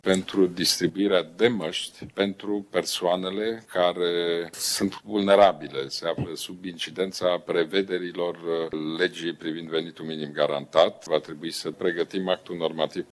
Premierul Ludovic Orban: